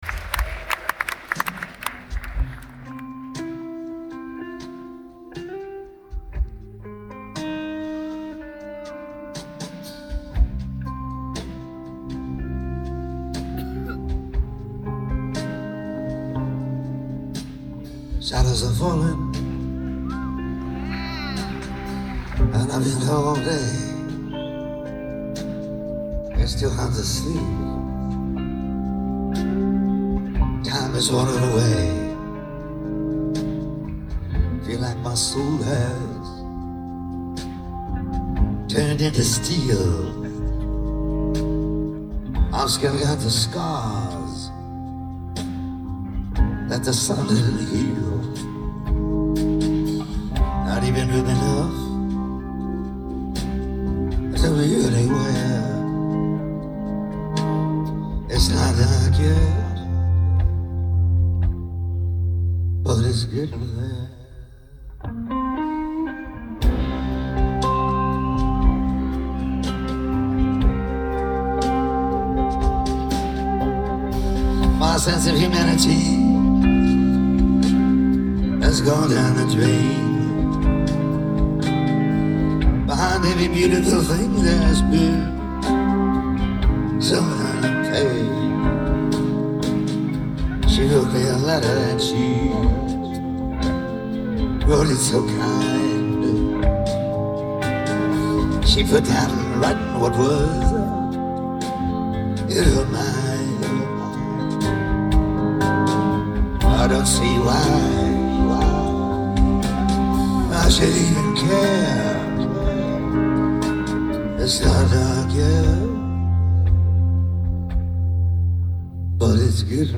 NEW YORK CITY, NEW YORK